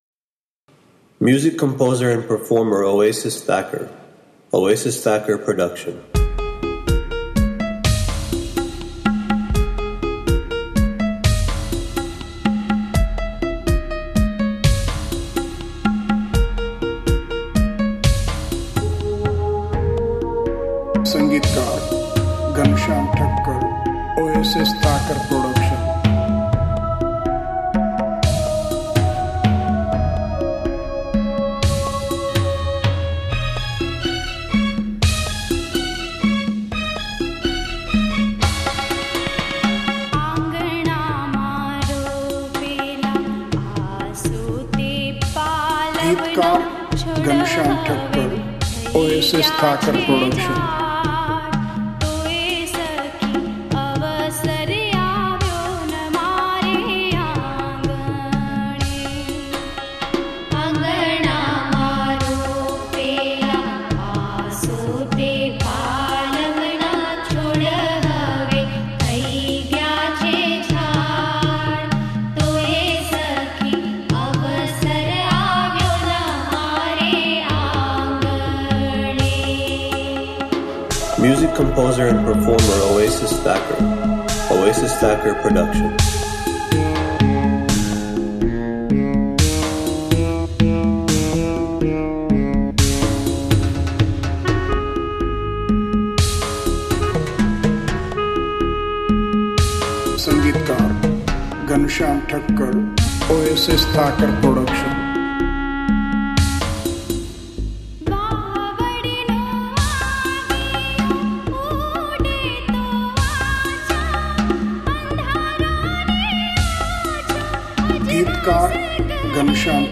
Chorous